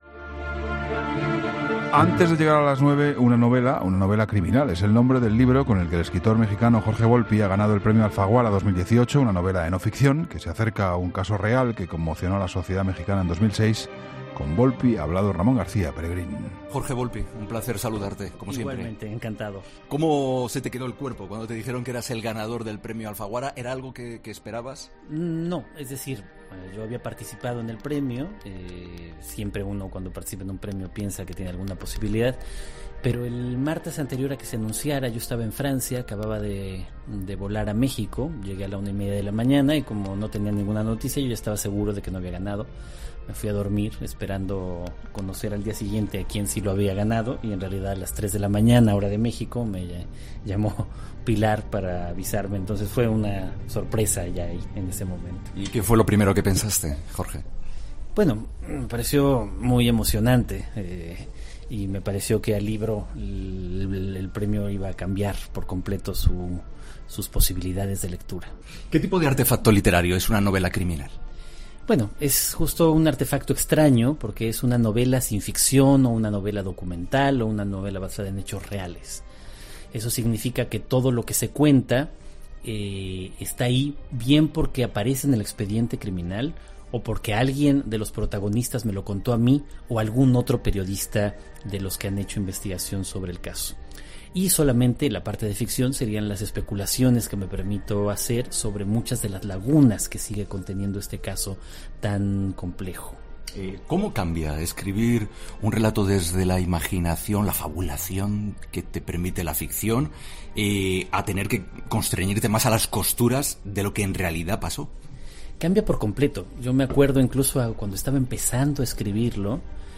Entrevistas en La Linterna
Jorge Volpi, ganador de la última edición del premio Alfaguara con 'Una novela criminal', ha contado en 'La Linterna' que escribir este libro “ha sido un trabajo apasionante” por mezclar componentes periodísticos y literarios